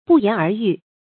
注音：ㄅㄨˋ ㄧㄢˊ ㄦˊ ㄧㄩˋ
不言而喻的讀法